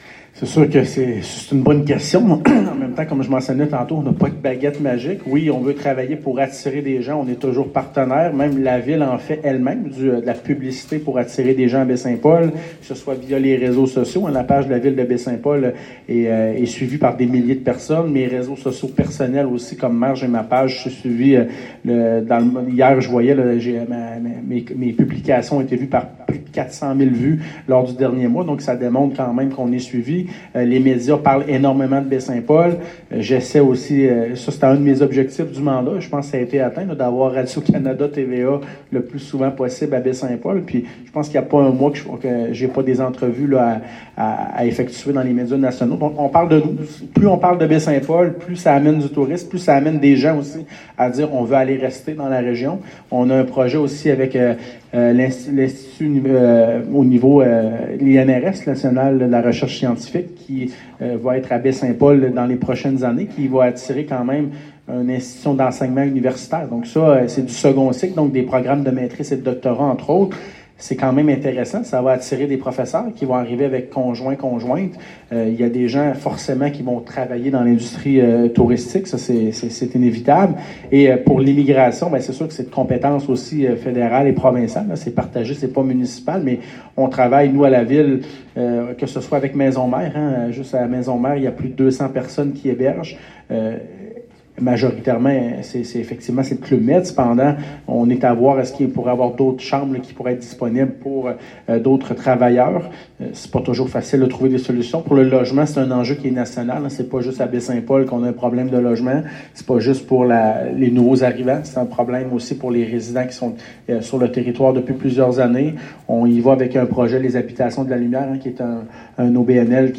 Une trentaine de convives s’étaient rassemblés dans l’ambiance chaleureuse et pittoresque de l’auberge de la rue Saint-Jean-Baptiste pour une séance de questions/réponses sans filtre.